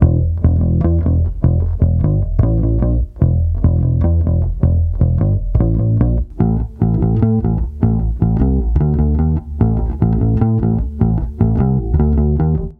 礼炮低音炮1
Tag: 75 bpm Rock Loops Bass Guitar Loops 2.15 MB wav Key : Unknown